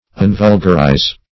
Search Result for " unvulgarize" : The Collaborative International Dictionary of English v.0.48: Unvulgarize \Un*vul"gar*ize\, v. t. [1st pref. un- + vulgarize.] To divest of vulgarity; to make to be not vulgar.